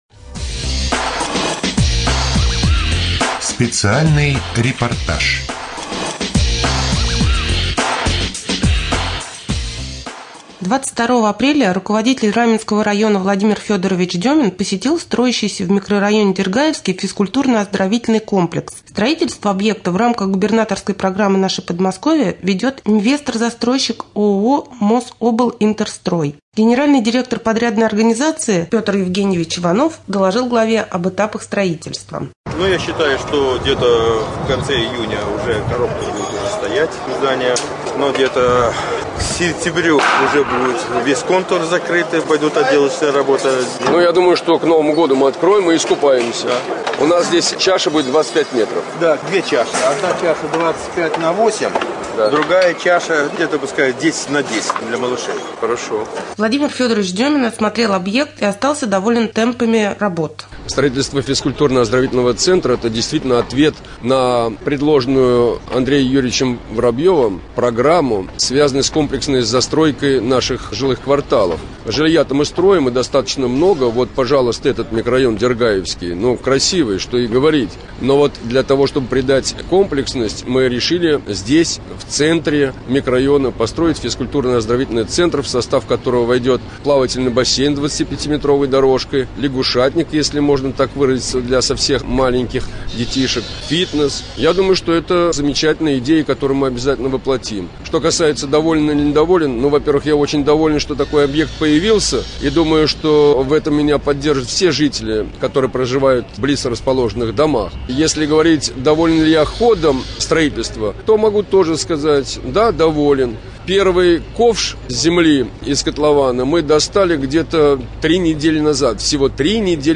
23.04.2013г. в эфире раменского радио - РамМедиа - Раменский муниципальный округ - Раменское
3. Рубрика «Специальный репортаж». К новому году в Раменском появится новый физкультурно-оздоровительный центр.